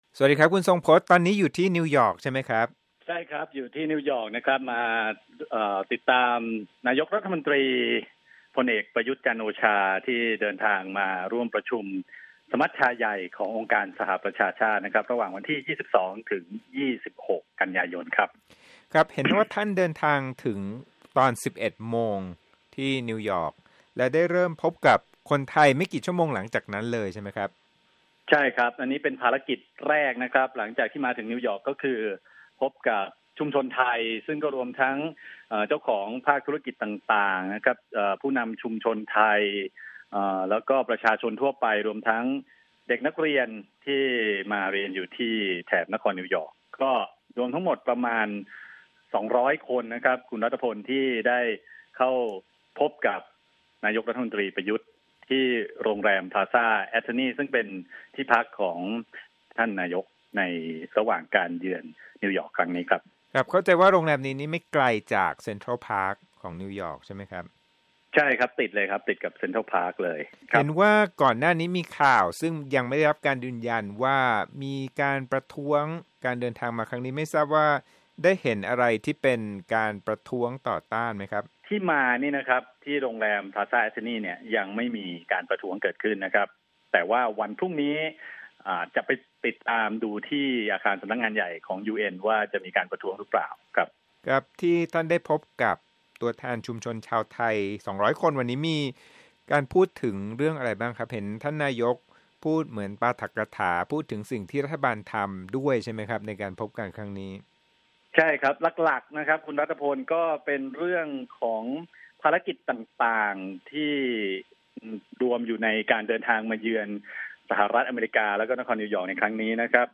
พล.อ. ประยุทธ์ จันทร์โอชา นายกรัฐมนตรี และรัฐมนตรีว่าการกระทรวงกลาโหม พบปะกับชาวชุมชนไทยที่ นครนิวยอร์ก ภายในห้องประชุมของโรงแรมพลาซา แอทธินี นครนิวยอร์ก ระหว่างการเดินทางเข้าร่วมประชุมสมัชชาใหญ่องค์การสหประชาชาติ สมัยสามัญ ครั้งที่ 74 ประจำปี พ.ศ.2562
นายกรัฐมนตรีของไทย ได้ขึ้นกล่าวทักทายและพูดคุยถึงการเดินทางในภารกิจครั้งนี้ นอกจากนี้ยังใช้โอกาสนี้ ชี้แจงนโยบายของรัฐบาลในด้านต่างๆ ให้กับชาวชุมชนไทยได้รับฟัง เช่นเดียวกับตอบข้อซักถาม และถ่ายรูปร่วมกันอย่างเป็นกันเอง